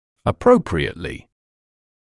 [ə’prəuprɪətlɪ][э’проуприэтли]надлежащим образом; правильно; по назначению
appropriately.mp3